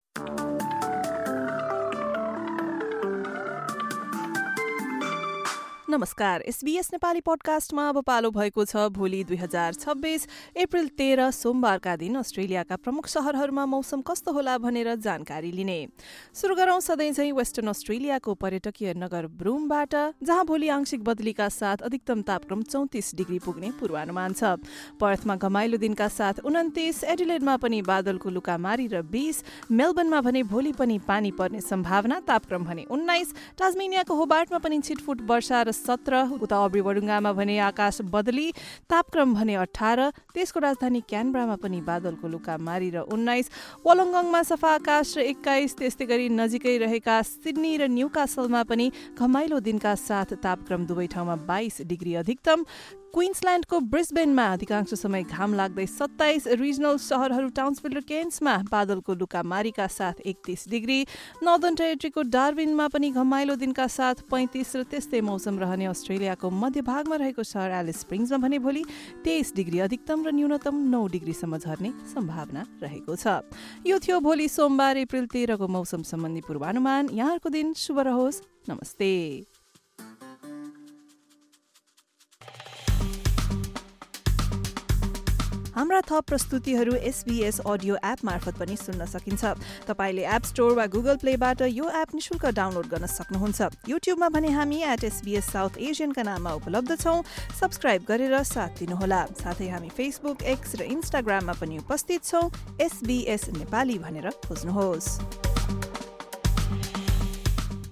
Weather update for major cities across Australia in Nepali. This update features tomorrow’s forecast for the following cities: Adelaide, Melbourne, Hobart, Albury-Wodonga, Sydney, Newcastle, Darwin and Alice Springs.